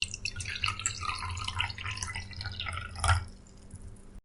Soda Pour
Soda Pour is a free sfx sound effect available for download in MP3 format.
Soda Pour.mp3